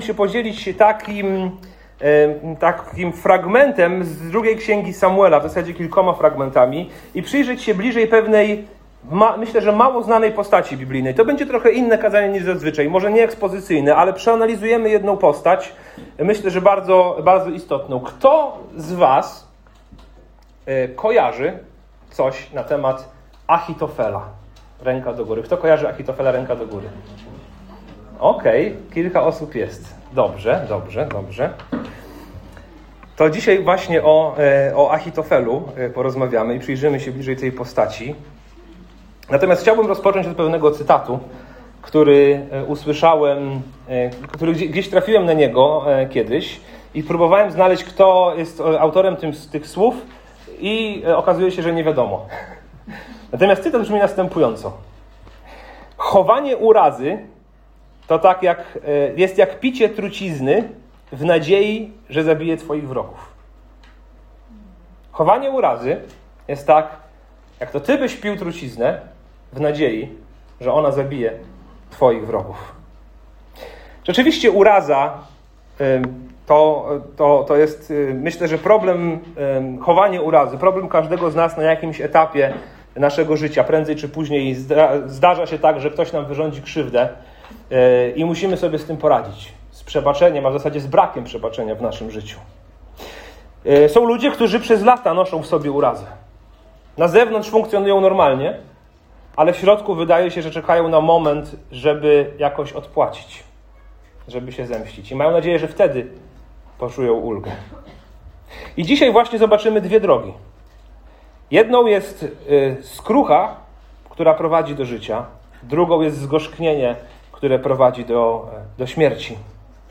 Jeśli kiedykolwiek nosiłeś w sobie urazę, która powoli Cię niszczyła, to to kazanie jest właśnie dla Ciebie. Posłuchaj historii Achitofela i Dawida – bo pokazuje ono, jak trucizna gniewu zabija od środka, a skrucha i przebaczenie przywracają życie.